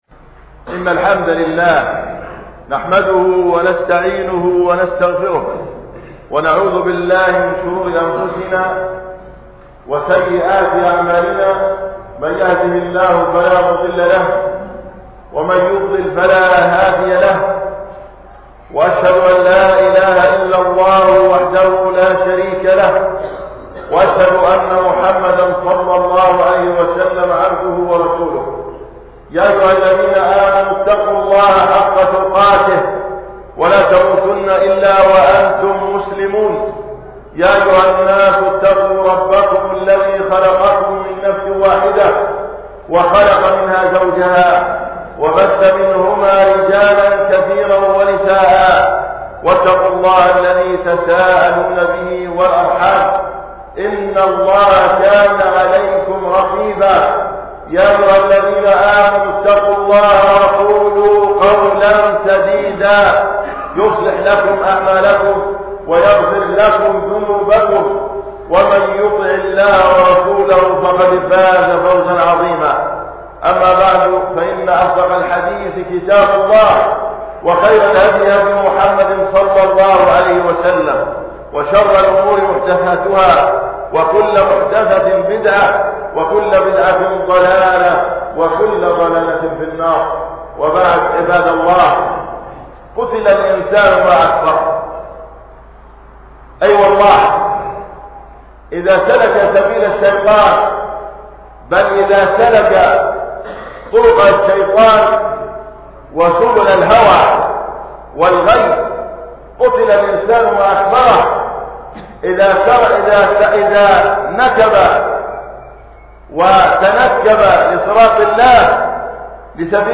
خطبة الجمعة { قُتل الإِنسان ما أكفره}
بمســجد الحـــمد – خان يونس